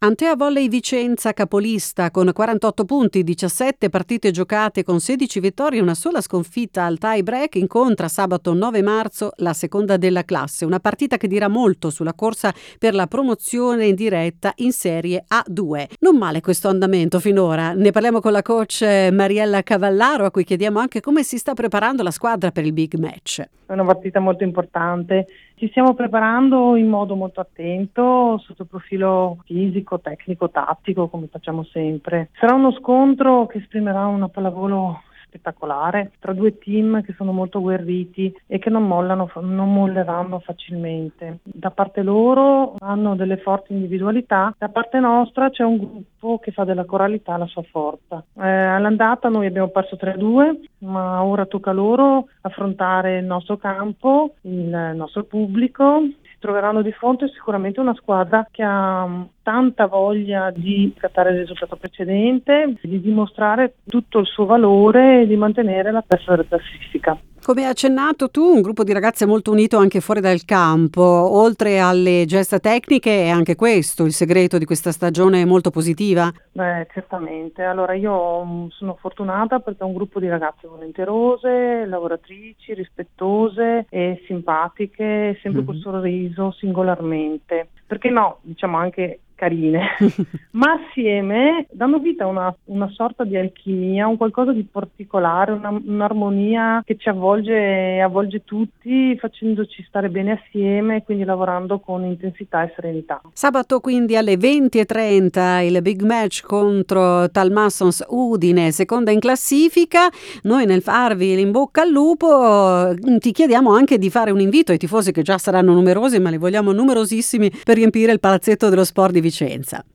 ANTHEA VOLEY VICENZA, L’INTERVISTA